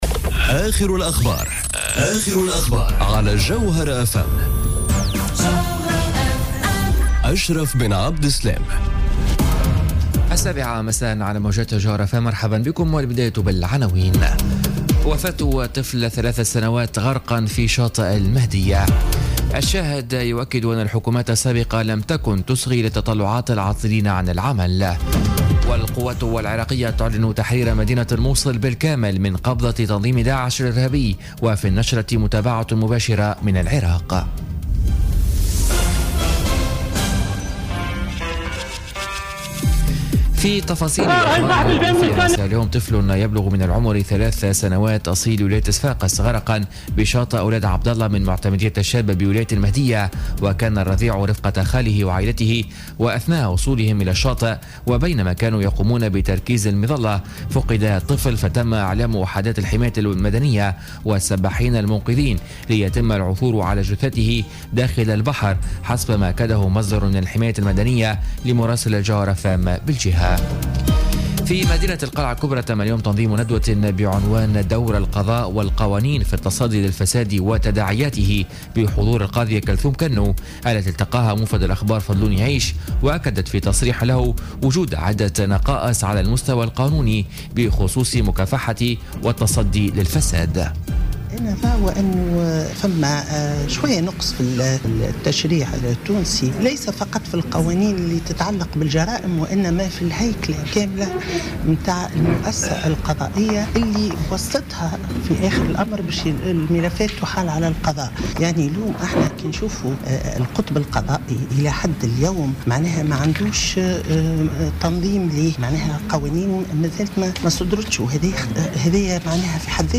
نشرة أخبار السابعة مساء ليوم السبت 08 جويلية 2017